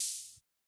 Index of /musicradar/retro-drum-machine-samples/Drums Hits/Tape Path B
RDM_TapeB_MT40-OpHat02.wav